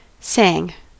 Ääntäminen
Ääntäminen US Tuntematon aksentti: IPA : /ˈsæŋ/ IPA : /seɪŋ/ Haettu sana löytyi näillä lähdekielillä: englanti Käännöksiä ei löytynyt valitulle kohdekielelle. Sang on sanan sing imperfekti.